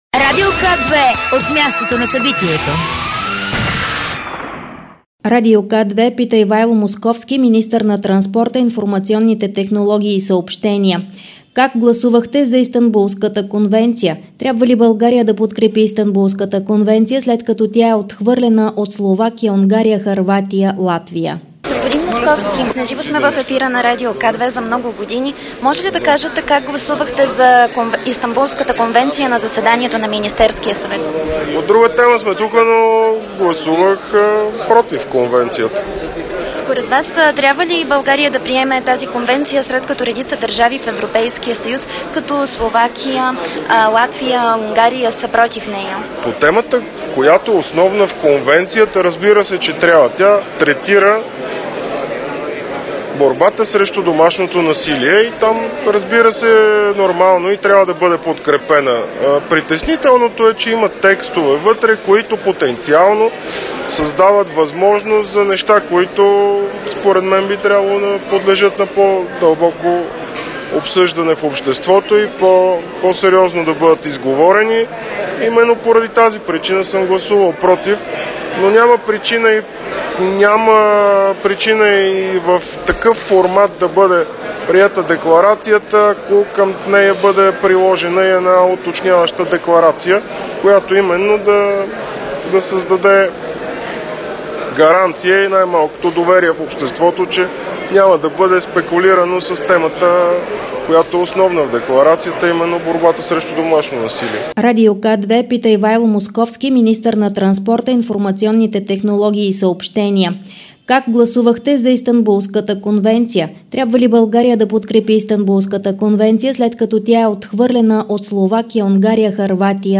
Изявление на Бойко Борисов, министър - председател на РБ след среща с премиера на Ирландия Лео Варадкар за : Българското председателство на Съвета на ЕС 2018, бъдещето на Европейския съюз, Брекзит, Балканите и на търговско-икономическото партньорство между България и Ирландия.